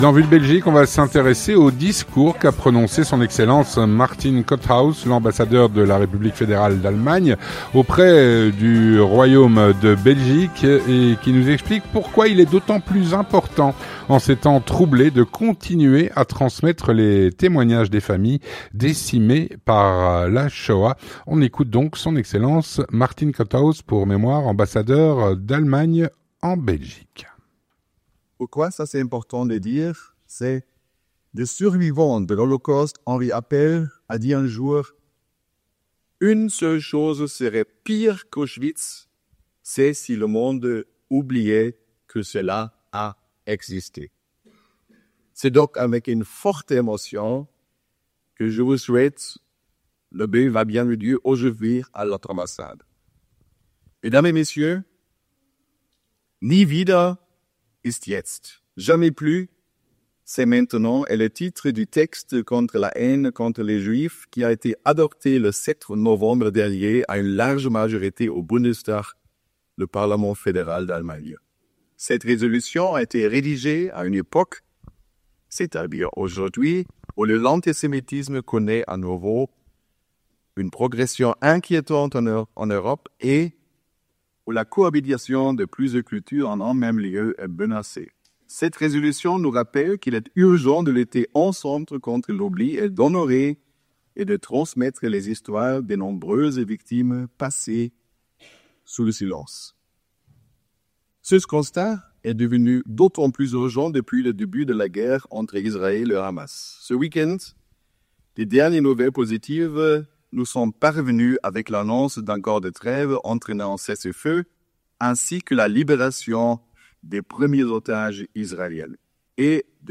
Vue de Belgique - Discours de S.E. Martin Kotthaus, ambassadeur d'Allemagne en Belgique. (27/01/2025)
On écoute le discours de S.E Martin Kotthaus, ambassadeur d’Allemagne en Belgique, qui nous explique pourquoi il est d’autant plus important, en ces temps troublés, de continuer à transmettre les témoignages des familles décimées par la Shoah.